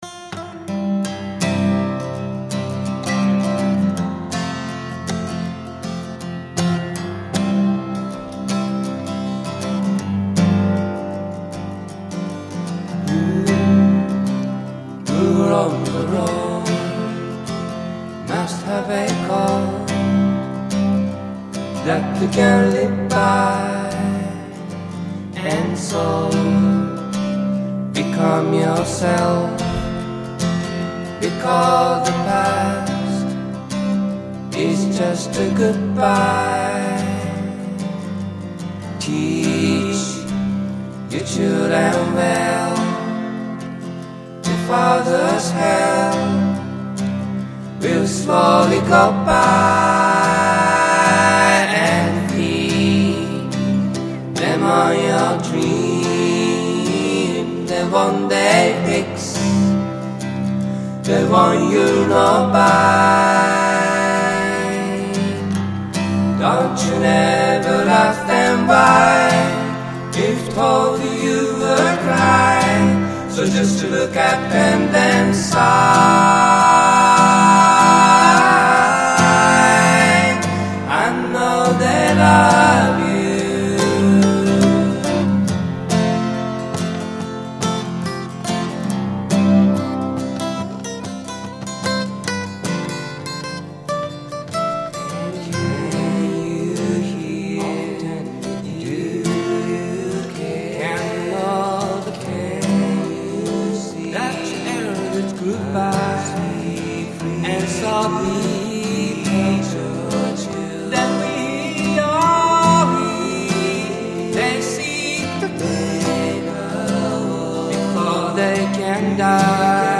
「Ｃａｋｅｗａｌｋ」で多重録音および編集、ボーカルマイクにはエフェクターを通している